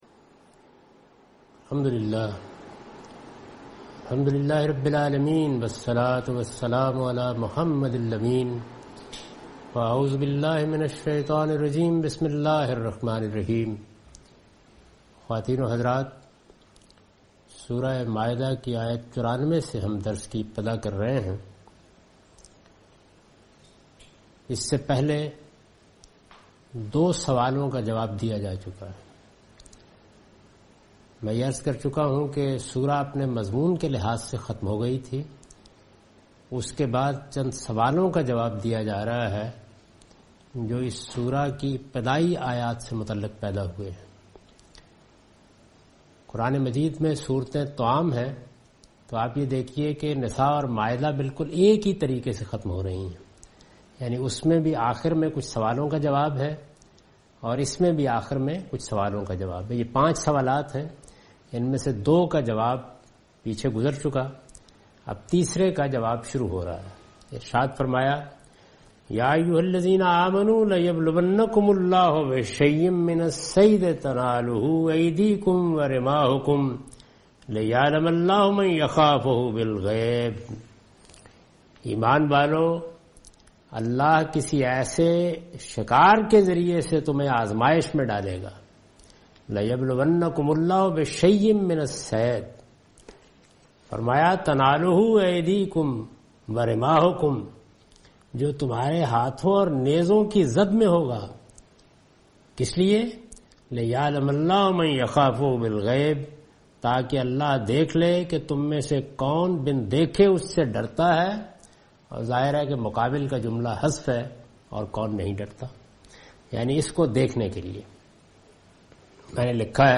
Surah Al-Maidah - A lecture of Tafseer-ul-Quran – Al-Bayan by Javed Ahmad Ghamidi. Commentary and explanation of verse 94 and 100